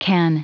Prononciation du mot ken en anglais (fichier audio)
Prononciation du mot : ken